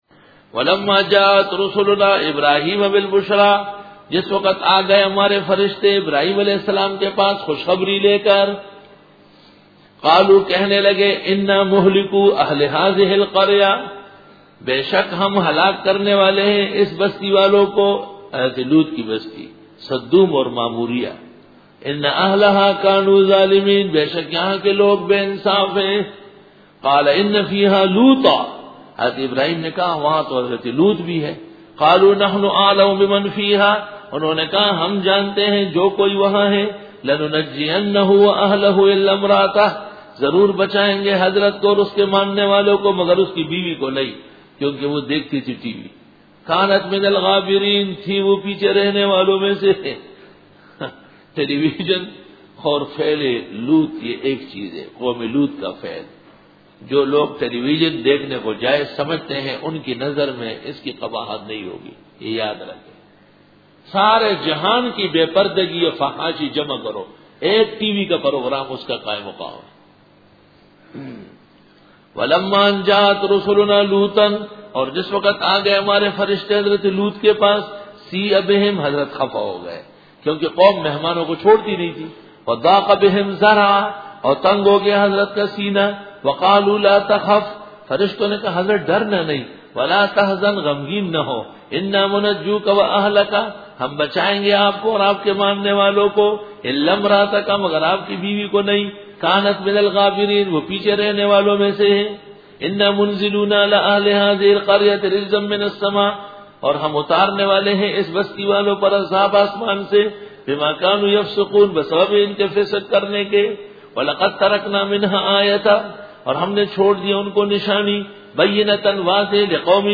Dora-e-Tafseer 2004